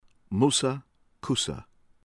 JIBRIL, MAHMOUD mah-MOOD   jeh-BREEL